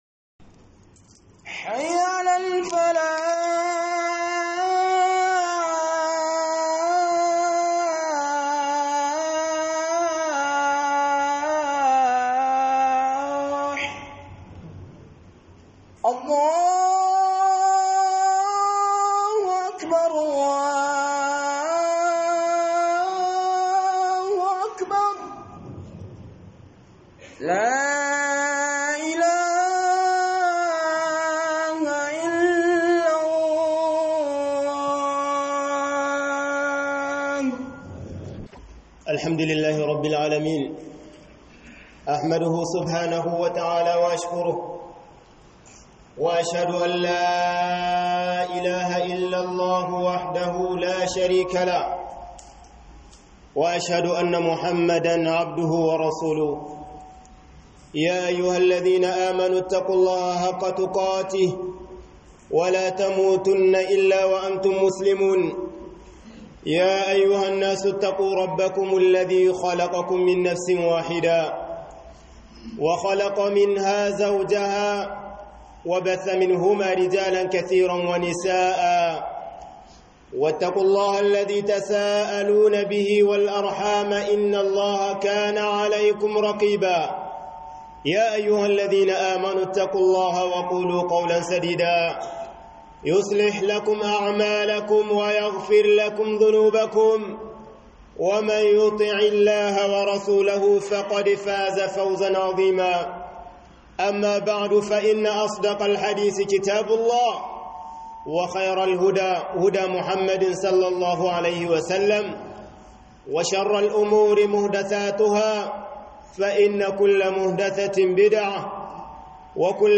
BAITUL MAKDIS - Huduba